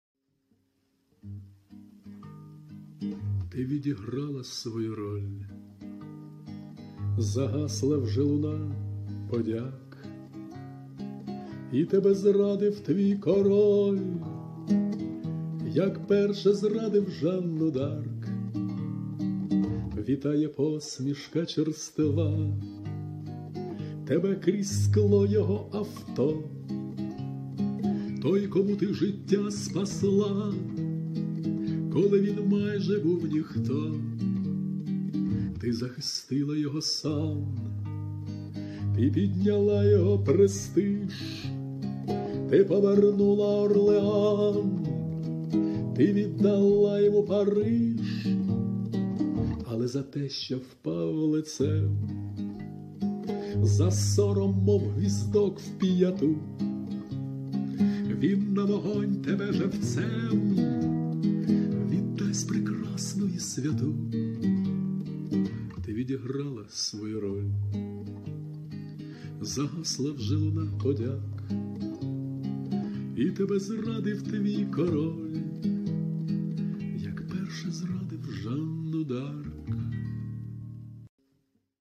Авторська пісня